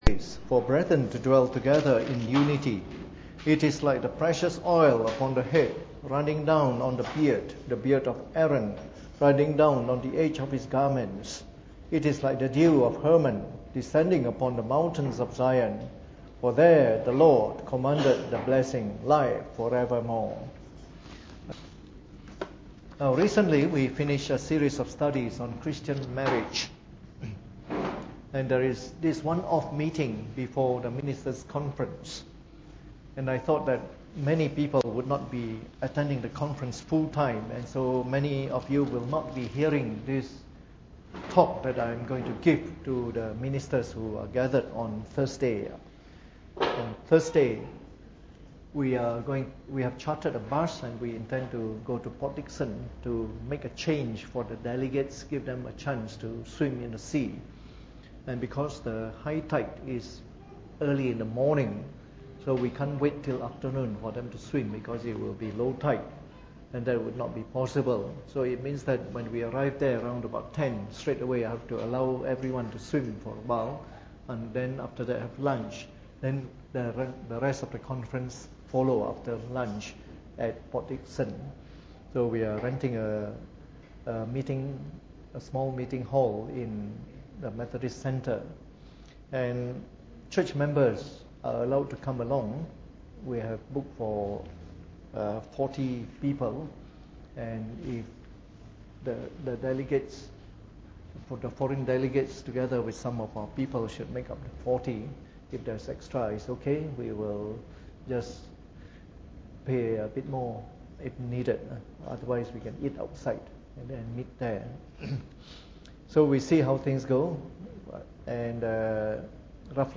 Preached on the 2nd of September 2015 during the Bible Study, an adapted version of one of the upcoming Reformed Ministers’ Conference talks.